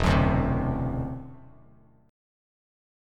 E7sus2#5 chord